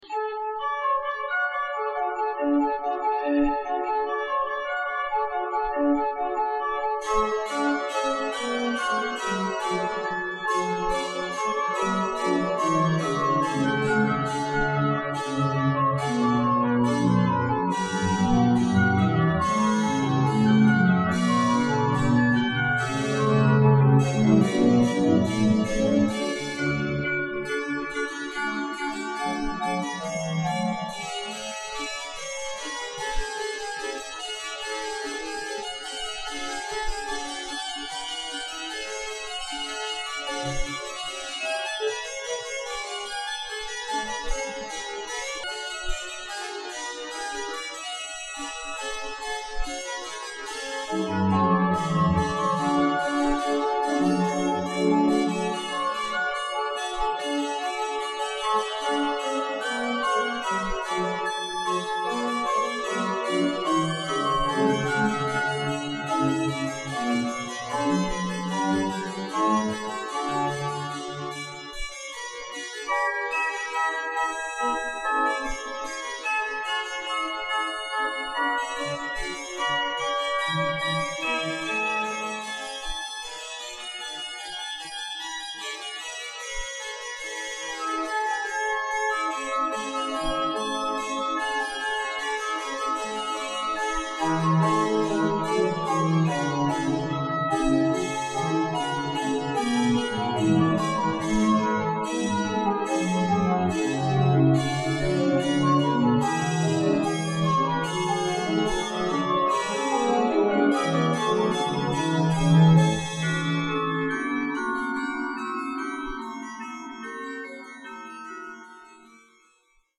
Concierto para Clave y �rgano
Iglesia de la Encarnaci�n de Marbella
clave+organo-frag.mp3